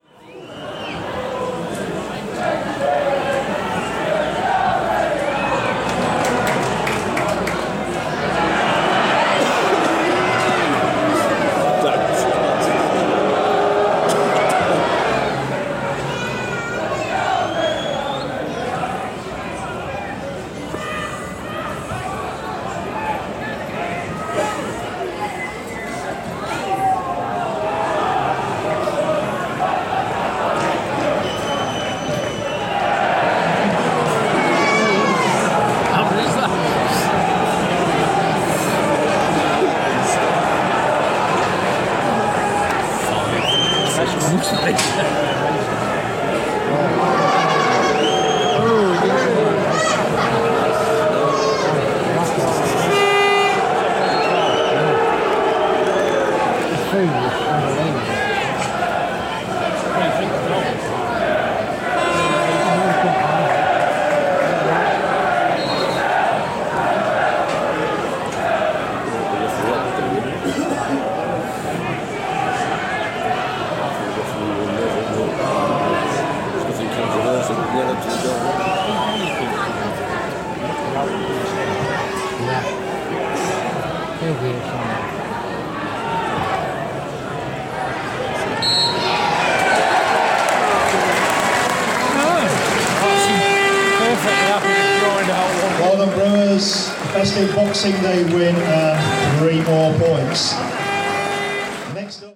The last minute of the match, 26.12.13. Crowd noise, announcements, ambience.